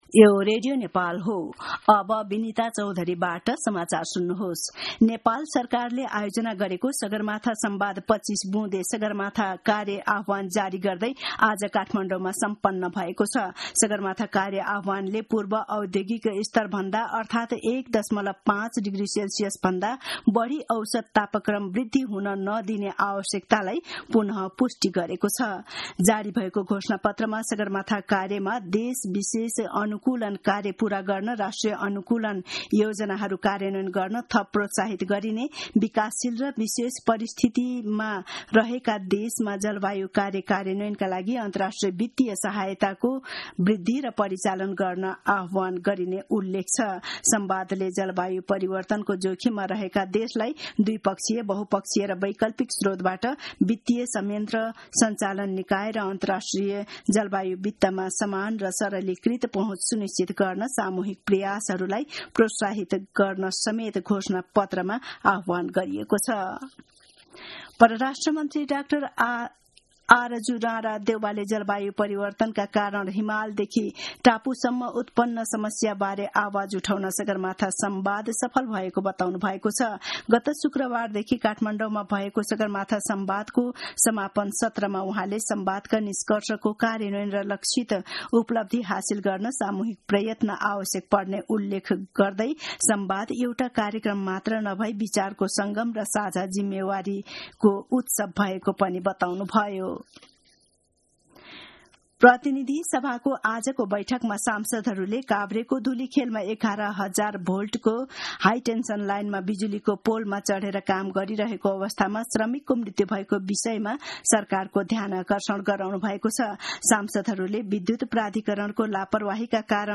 दिउँसो ४ बजेको नेपाली समाचार : ४ जेठ , २०८२
4-pm-news-1-2.mp3